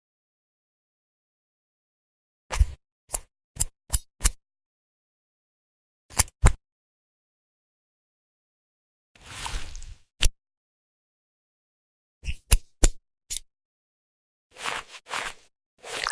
ASMR Cleaning Video 📹 sound effects free download